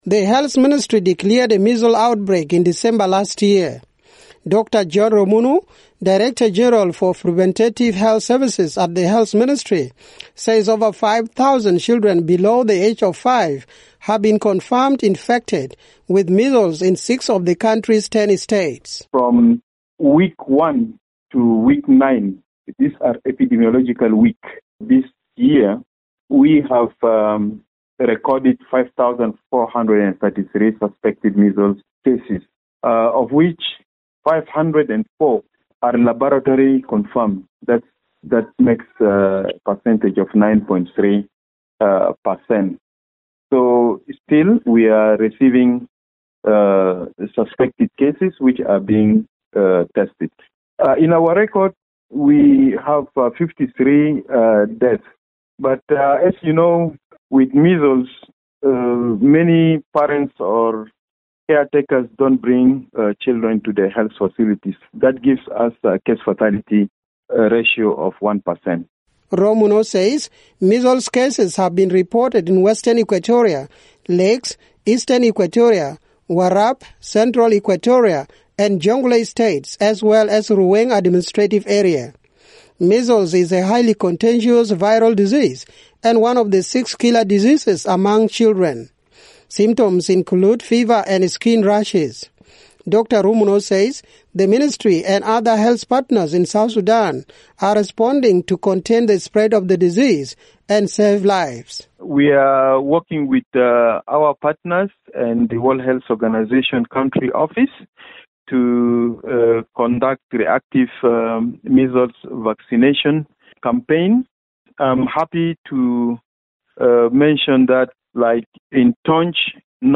reports from Juba.